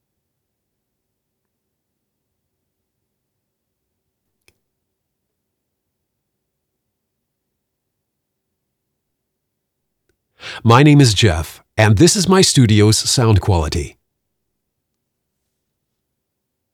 Male
Assured, Authoritative, Character, Conversational, Corporate, Engaging, Friendly, Reassuring, Smooth, Versatile, Warm
neutral American English (native), New York, Southern hard-R, Southern soft-R, West-Coast = California -> Washington State, British Columbia, German and various European accents
Main Reel.mp3
Microphone: Sennheiser MKH 416, Sennheiser MK4